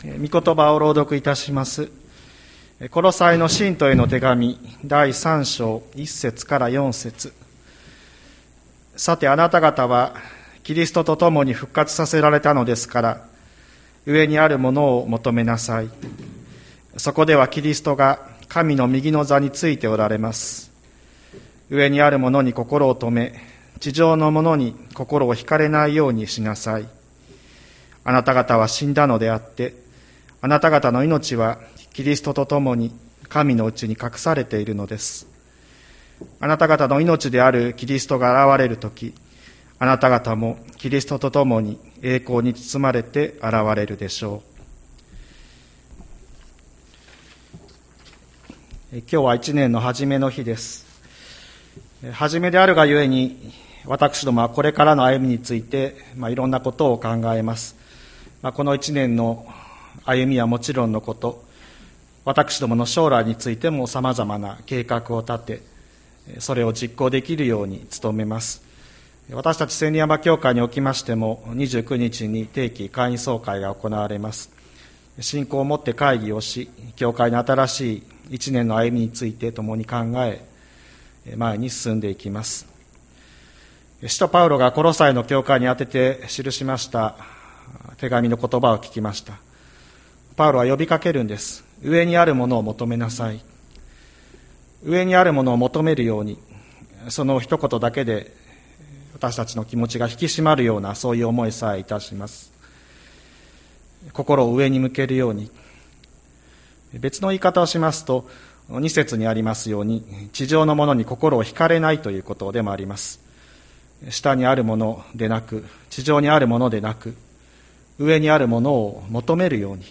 2023年01月01日朝の礼拝「こころを高く上げよう！」吹田市千里山のキリスト教会